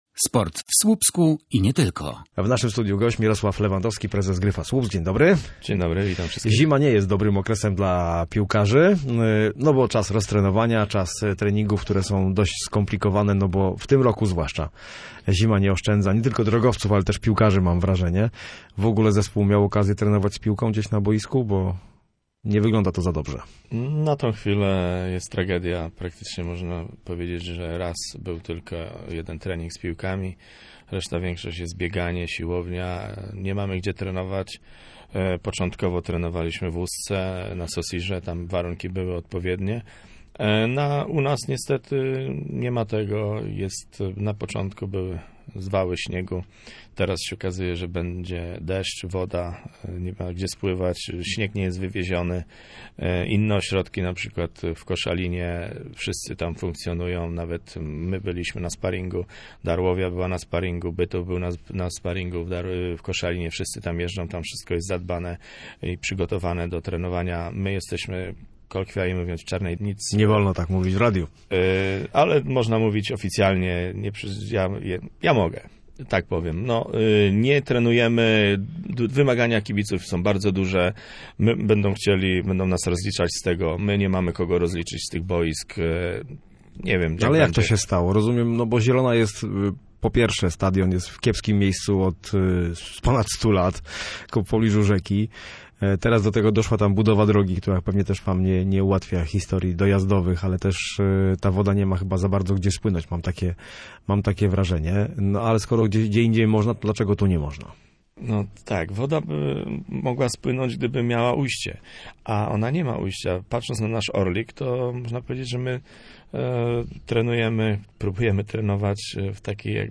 był gościem miejskiego programu Radia Gdańsk Studio Słupsk 102 FM.